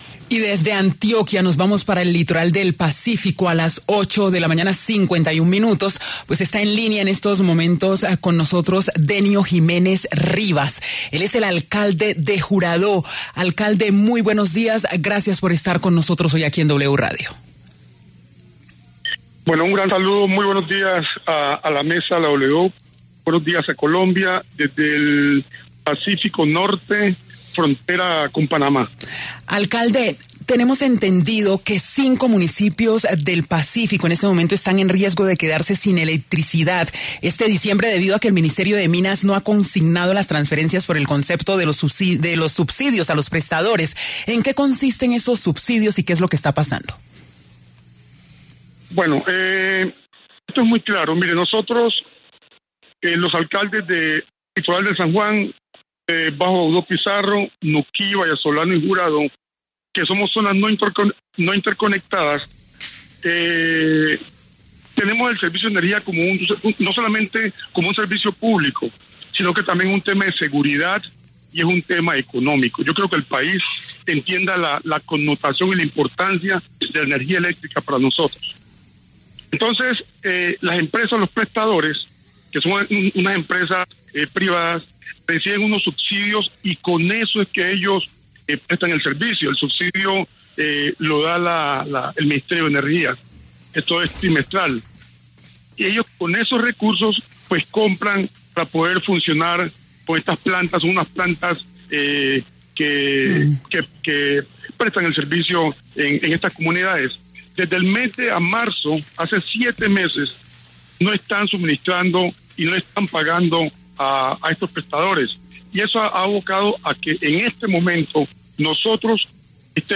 Denio Jiménez Rivas, alcalde de Juradó en el departamento de Chocó, pasó por los micrófonos de La W, con Julio Sánchez Cristo, para hablar del riesgo que tienen cinco municipios en el Pacífico colombiano de quedarse sin electricidad ante la falta de pago del Ministerio de Minas no ha consignado las transferencias de los subsidios a los prestadores de servicio.